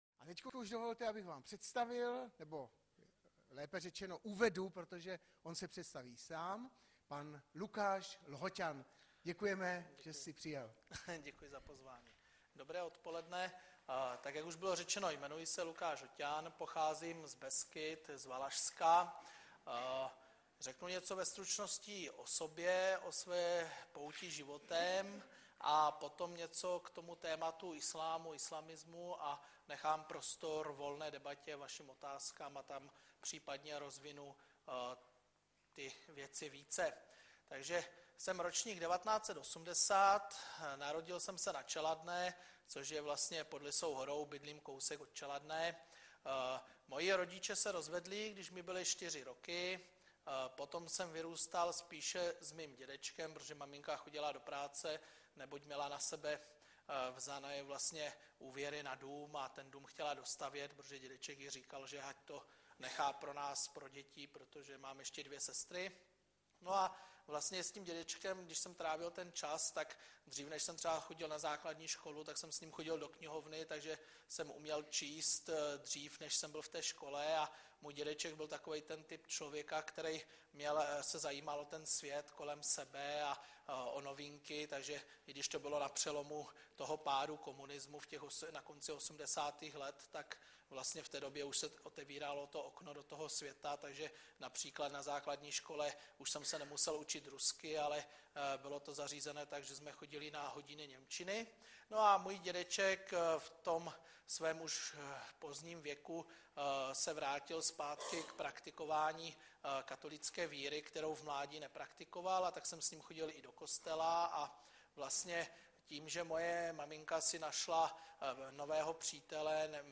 Webové stránky Sboru Bratrské jednoty v Litoměřicích.
PŘEDNÁŠKA O ISLÁMU